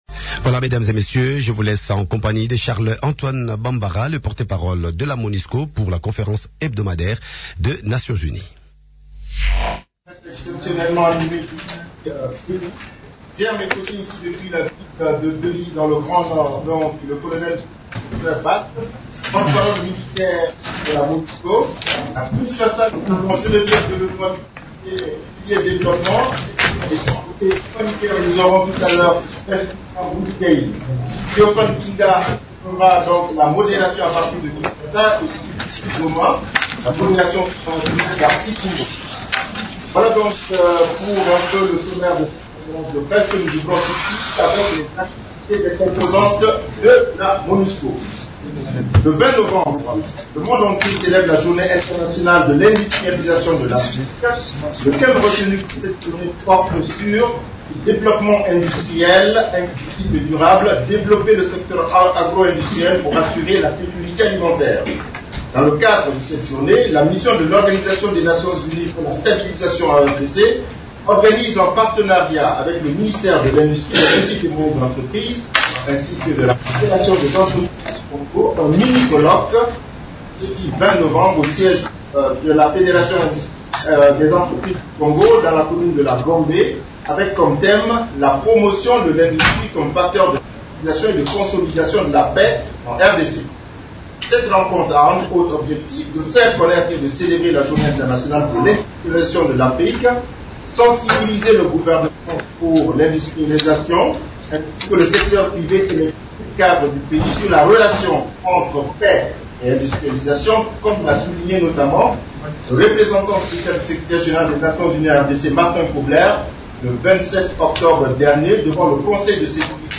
Conférence de presse du 19 novembre 2014
La conférence hebdomadaire des Nations unies du mercredi 19 novembre à Kinshasa a abordé les sujets suivants :